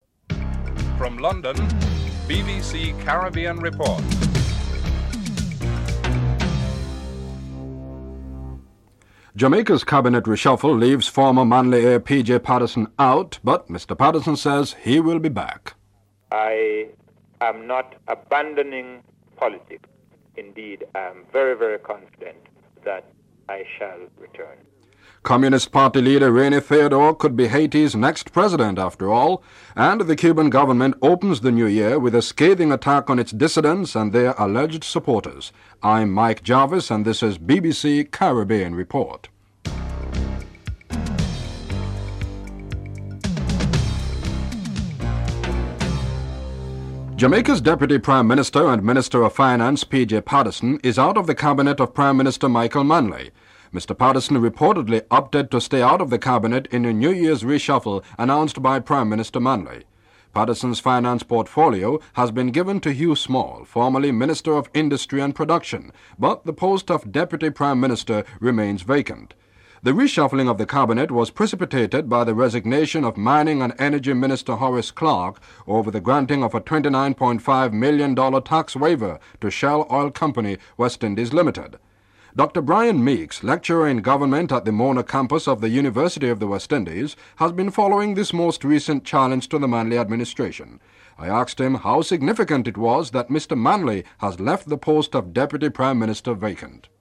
2. Report on the withdrawal of P. J. Patterson from the Jamaican cabinet (00:47-01:37)
6. Report on the situation in Haiti (07:09-07:38)
10. Report on election campaign in Guyana (12:15-12:51)